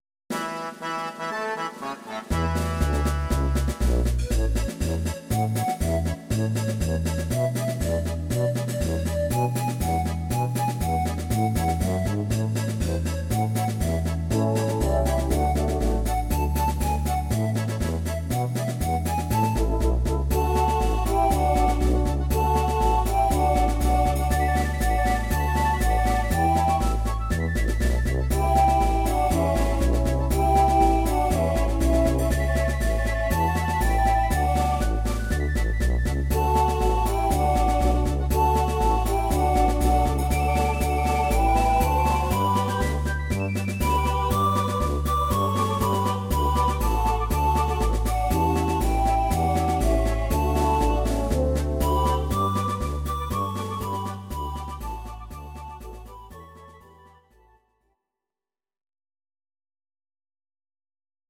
Audio Recordings based on Midi-files
German, Medleys